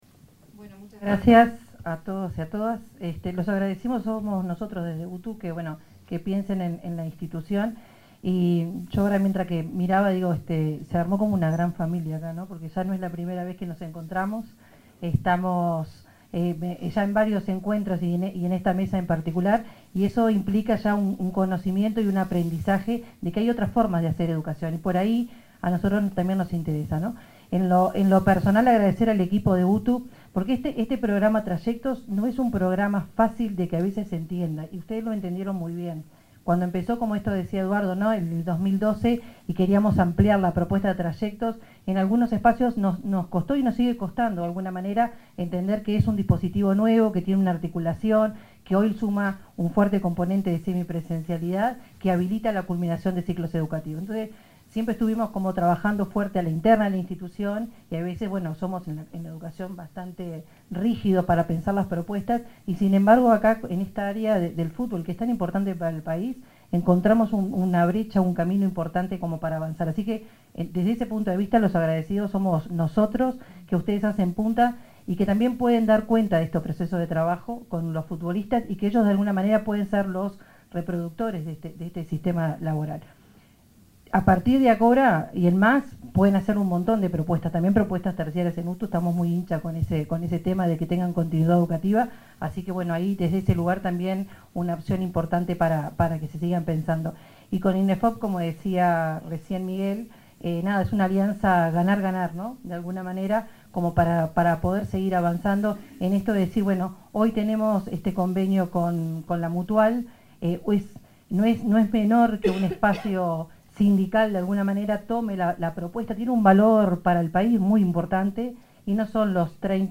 Palabras de la directora general de UTU, Virginia Verderese
Palabras de la directora general de UTU, Virginia Verderese 01/09/2025 Compartir Facebook X Copiar enlace WhatsApp LinkedIn Durante el lanzamiento de la tercera edición del bachillerato profesional para futbolistas, la directora general de la Universidad del Trabajo del Uruguay (UTU), Virginia Verderese, se refirió a las características principales de este plan de estudios.